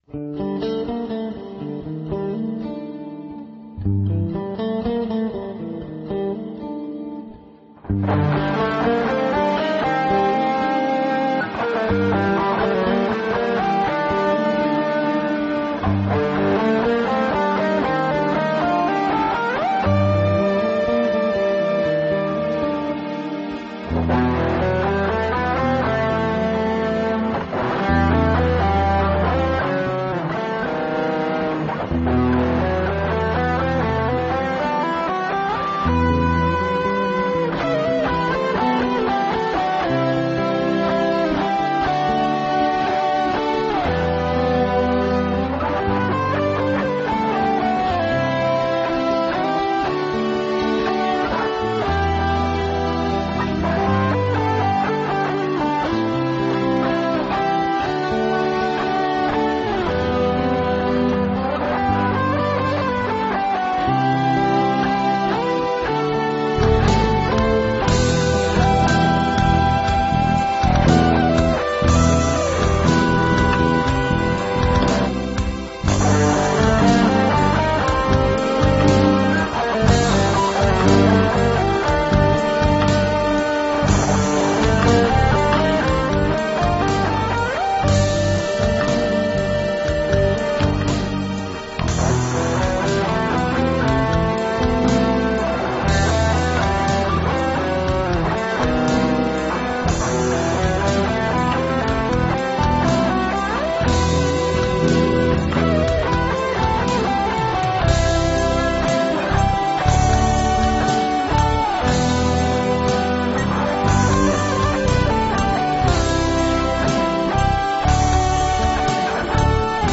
инструментальная композиция